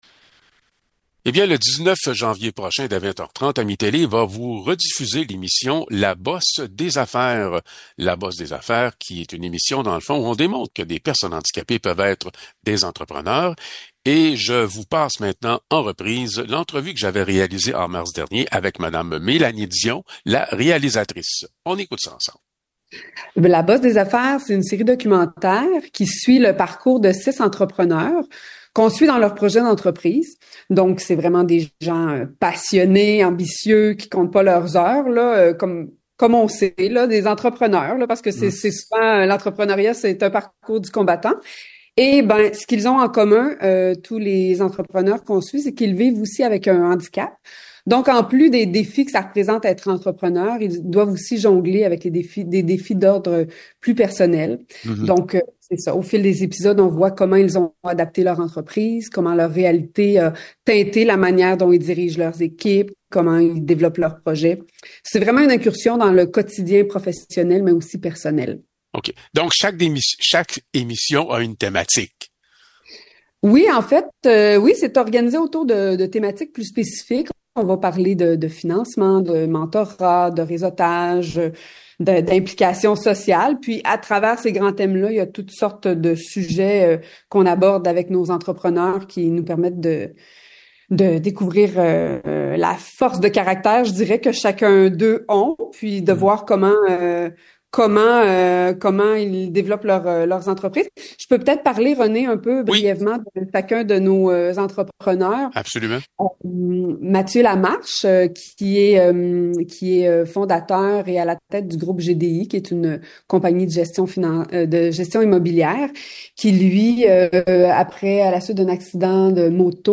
Nous continuons nos entrevues avec les artisans d’AMI-Télé.